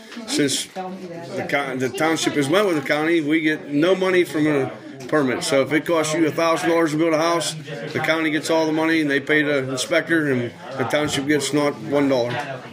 Supervisors Chairman Matt Housholder said that they went with TKL because the township was not receiving any money for building permits.